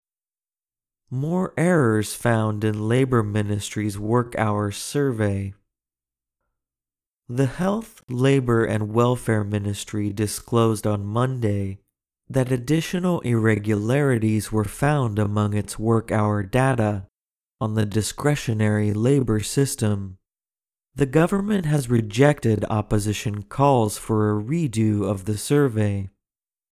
ネイティブ音声のリズムや抑揚に気を付け、完全にコピーするつもりで通訳トレーニングを反復してくださいね。